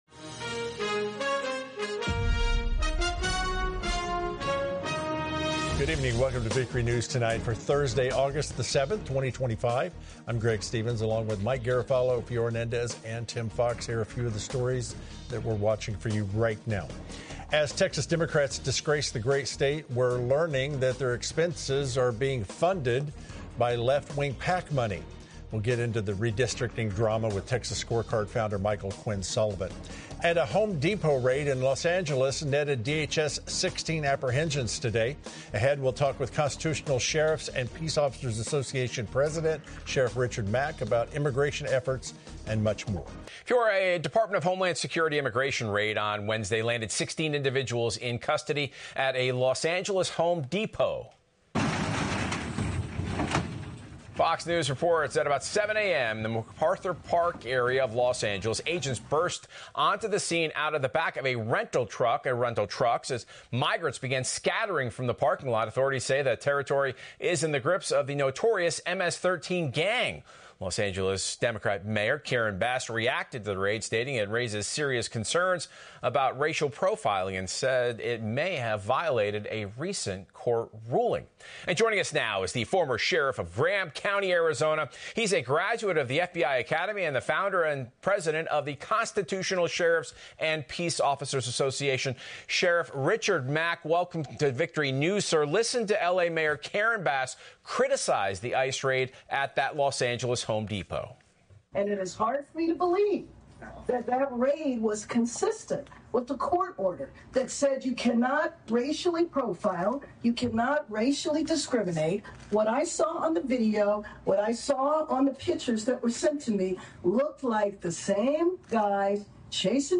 The report also includes an interview with Sheriff Richard Mack, who supports the raid and criticizes Bass and Governor Newsom for their handling of the situation. He also compares President Trump’s actions to stop LA riots to JFK’s actions in the 60s to protect civil rights.